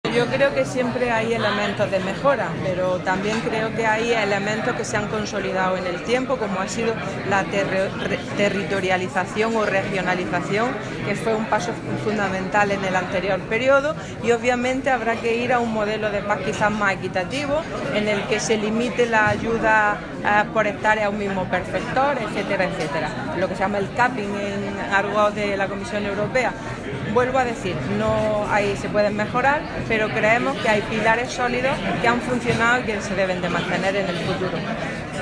Declaraciones consejera presupuesto PAC